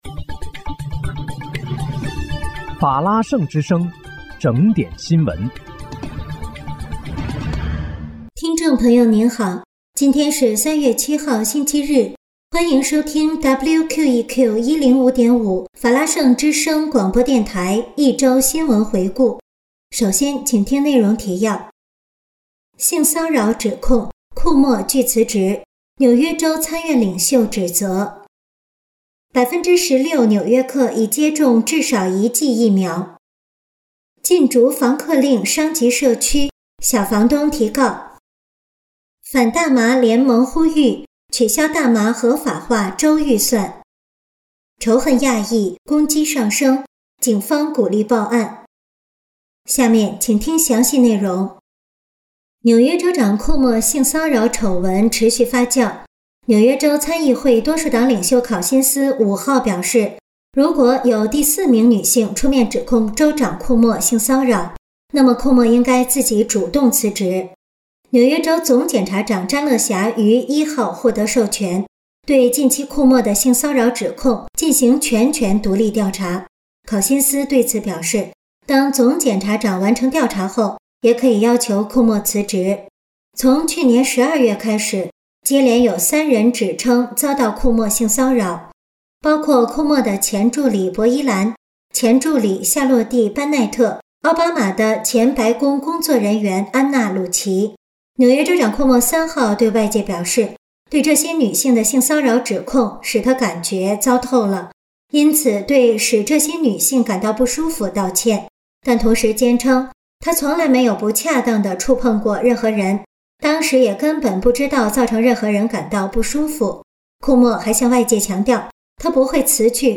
3月7日（星期日）一周新闻回顾